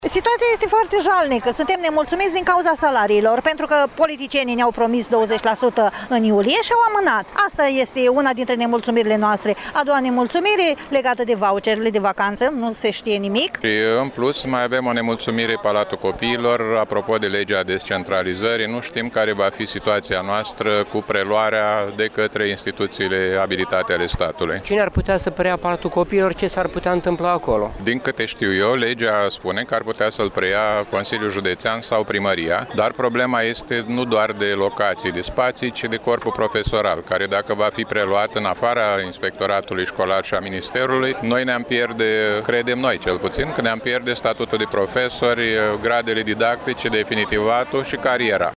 150 de cadre didactice din mediul preuniversitar şi muzeografi din Iaşi au protestat, astăzi, timp de o oră, în faţa Prefecturii, nemulţumiţi de legea salarizării unitare.
29-mai-rdj-12-vox-pop-profesori.mp3